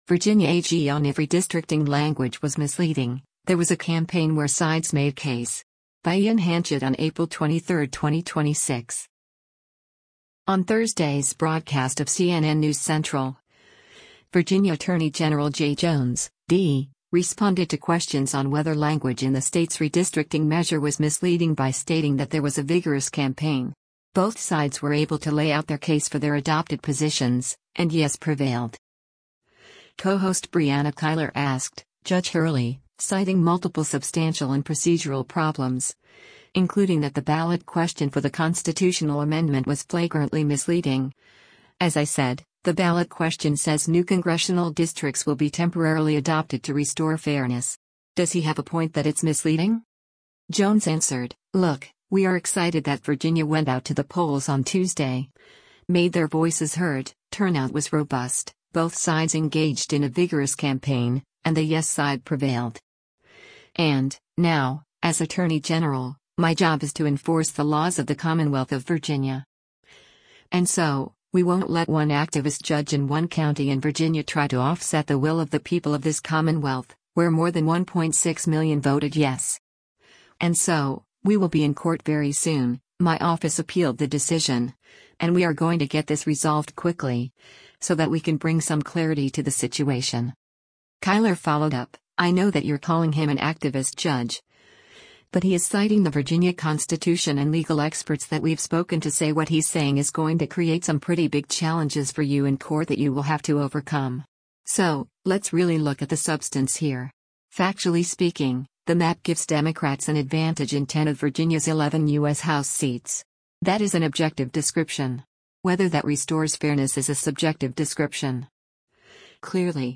On Thursday’s broadcast of “CNN News Central,” Virginia Attorney General Jay Jones (D) responded to questions on whether language in the state’s redistricting measure was misleading by stating that “there was a vigorous campaign. Both sides were able to lay out their case for their adopted positions, and yes prevailed.”